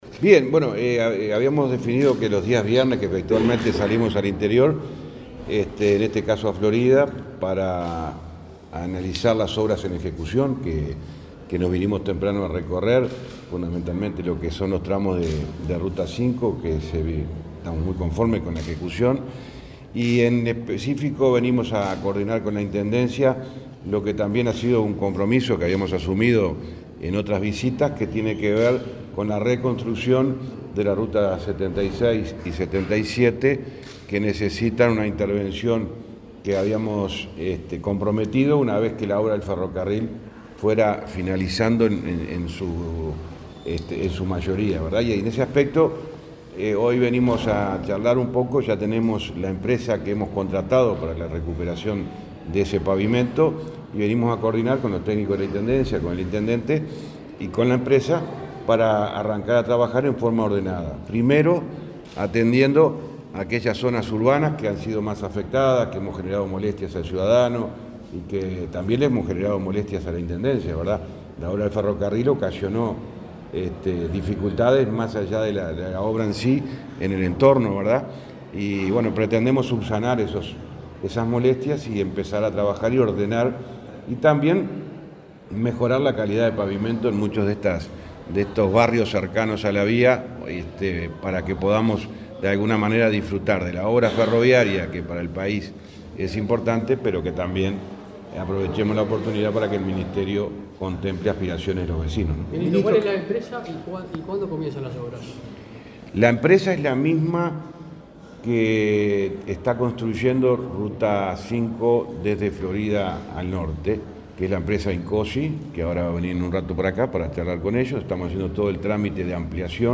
Declaraciones del ministro de Transporte, José Luis Falero
El ministro de Transporte, José Luis Falero, dialogó con la prensa en Florida, luego de reunirse con el intendente local, Guillermo López.